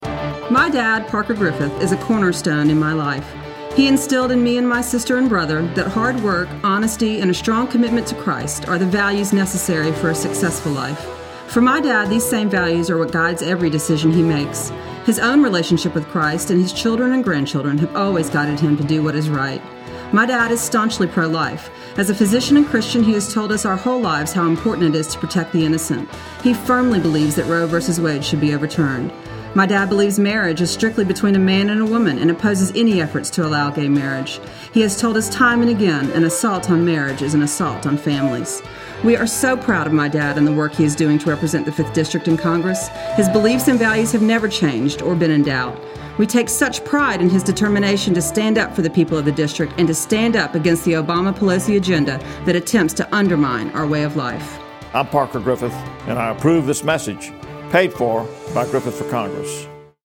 Congressman Parker Griffith-Radio Spot
CPG_RadioSpot01.mp3